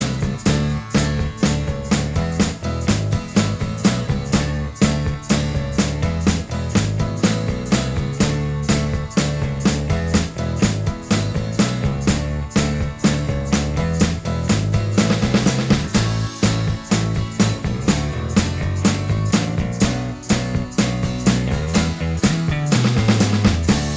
Minus Lead Guitar Pop (1980s) 4:38 Buy £1.50